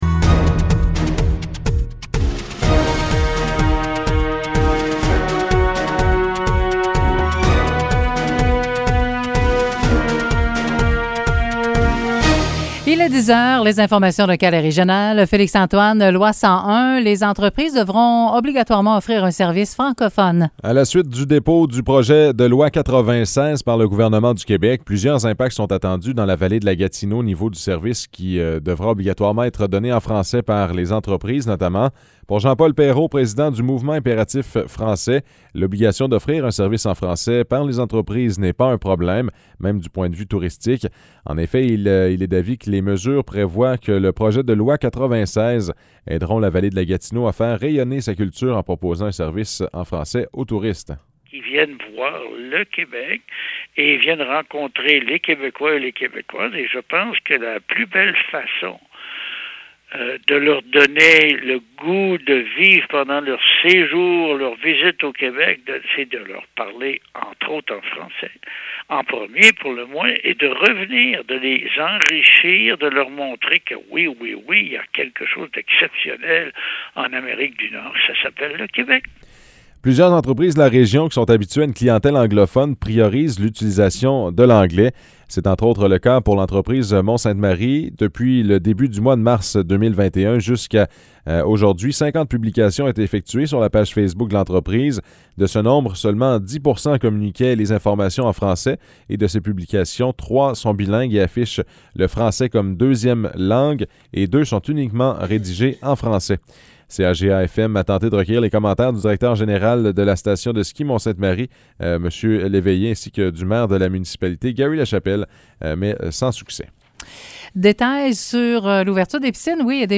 Nouvelles locales - 15 juin 2021 - 10 h